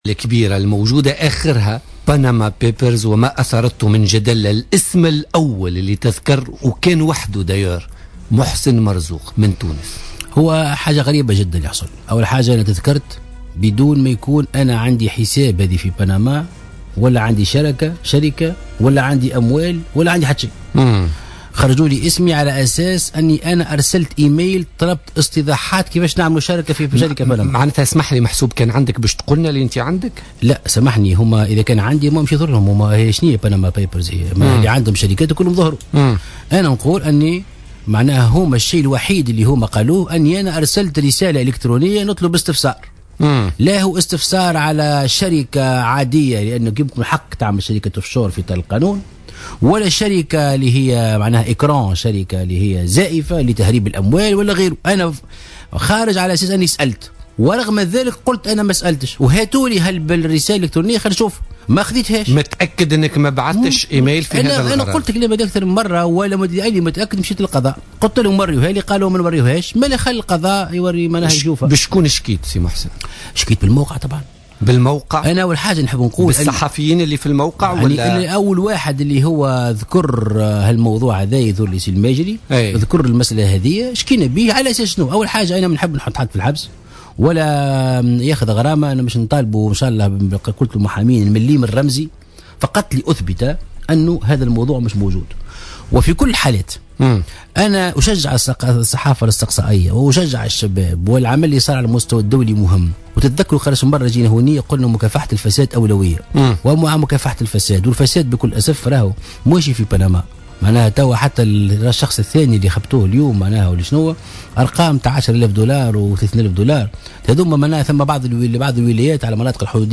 قال محسن مرزوق رئيس حركة مشروع تونس ضيف بوليتيكا اليوم الإثنين 11 أفريل 2016 أن اسمه ذكر في فضيحة "وثائق بنما" دون أن يكون له حساب أو شركة أو أموال في بنما